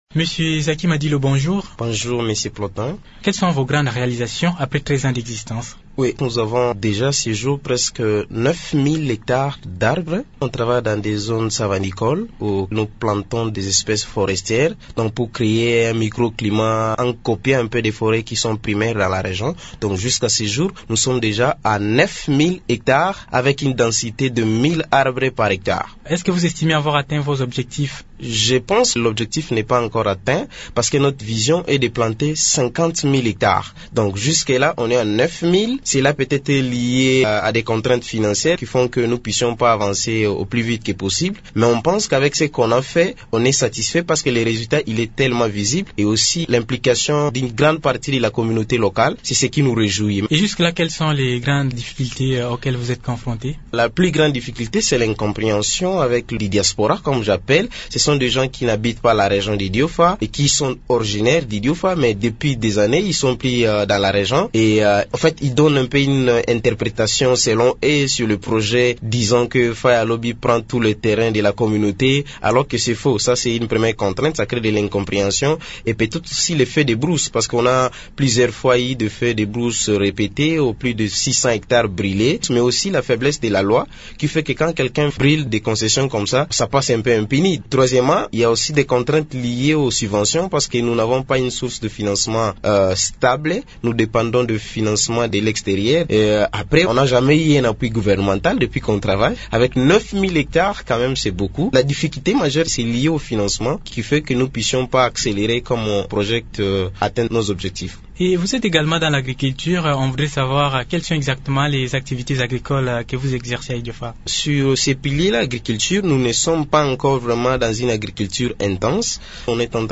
lors d’une interview accordée à Radio Okapi